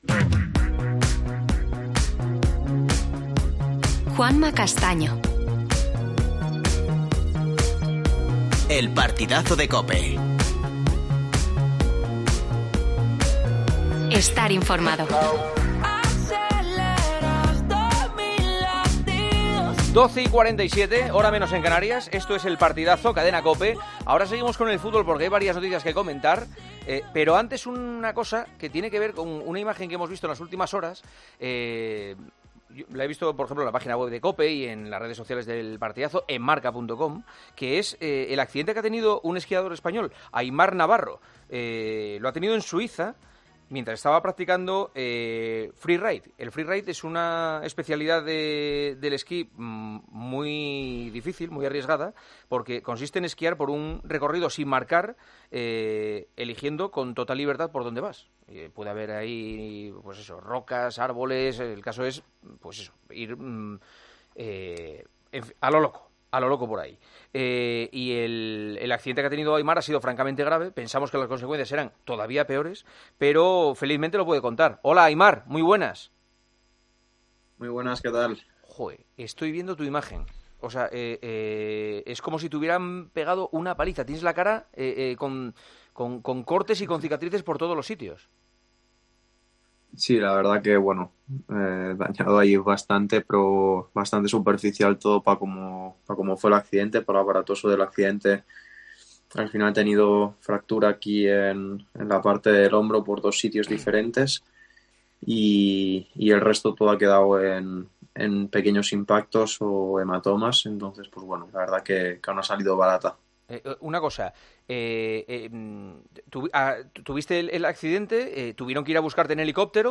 Hablamos con el esquiador 'freeride' que nos cuenta la dura caída descontrolada que sufrió y que le provocó un fuerte golpe contra las rocas.